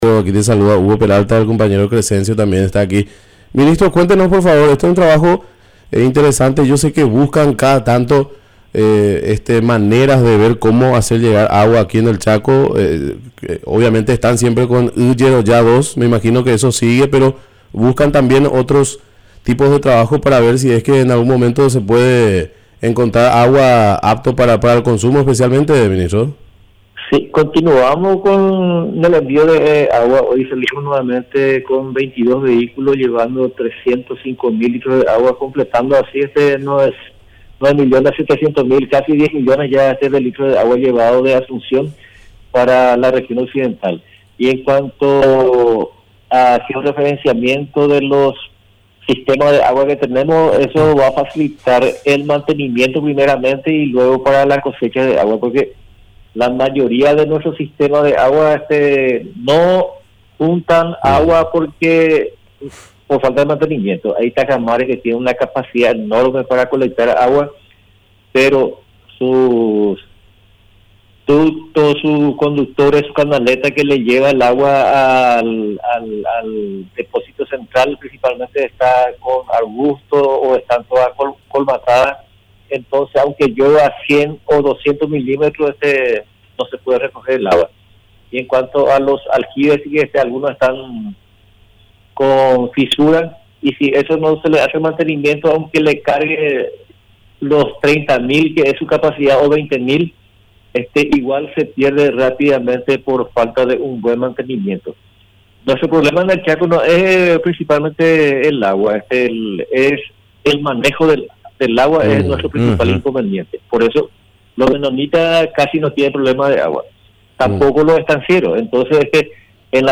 Entrevistas / Matinal 610 Estudio de geo-referenciamiento del agua Oct 28 2024 | 00:11:16 1x 00:00 / 00:11:16 Subscribe Share RSS Feed Share Link Embed